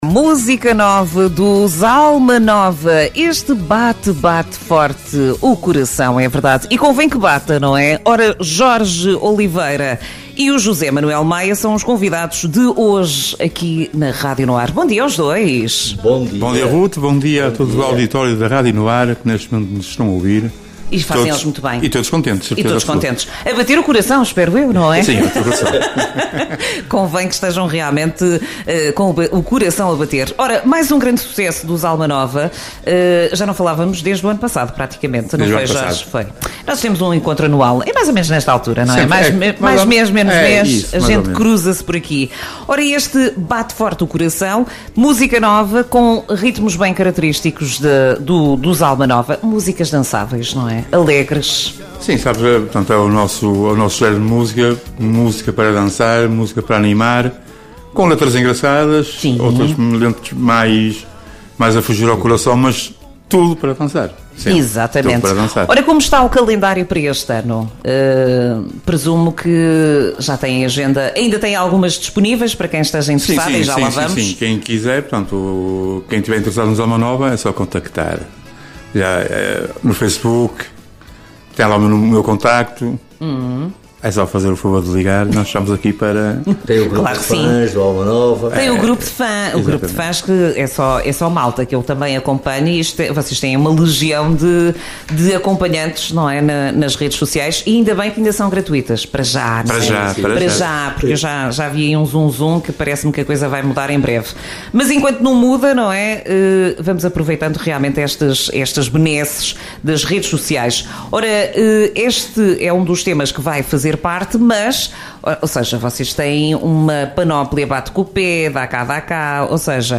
ENTREVISTA-ALMA-NOVA-2025.mp3